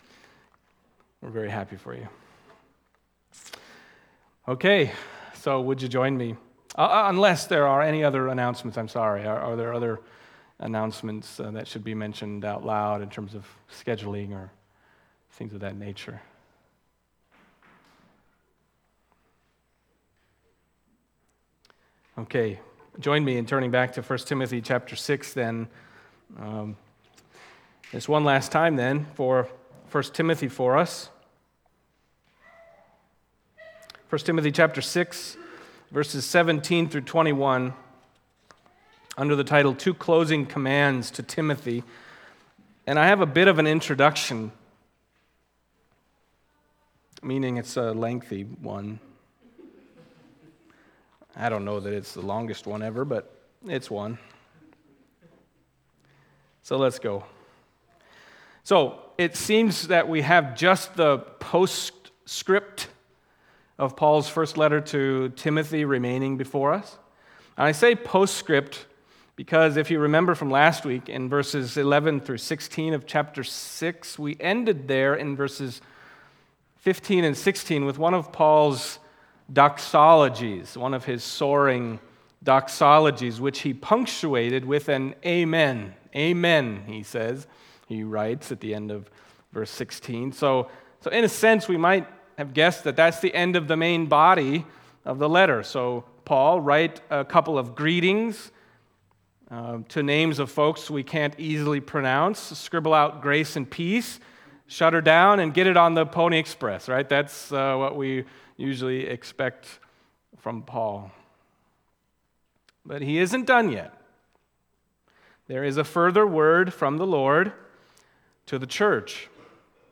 1 Timothy Passage: 1 Timothy 6:17-21 Service Type: Sunday Morning 1 Timothy 6:17-21 « A Charge to the Man of God Let Us Provoke One Another…